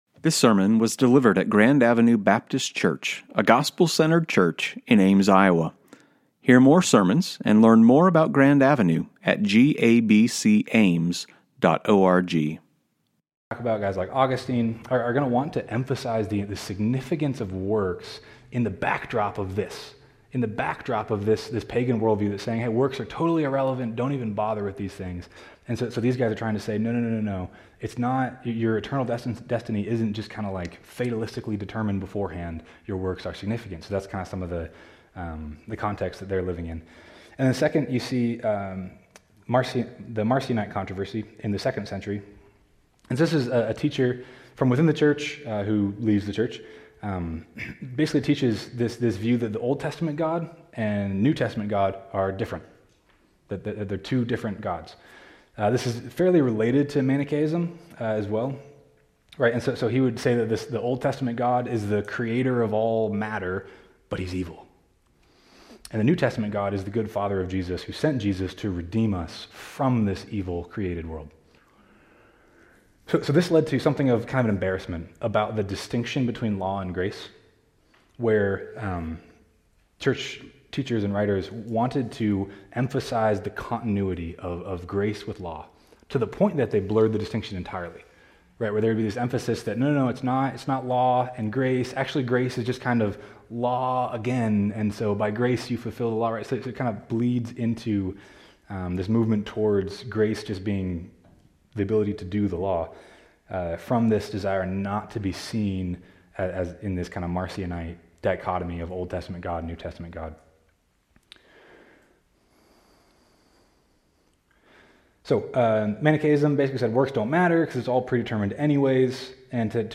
2025 Author GABC Soundbooth Category One Day Seminar Date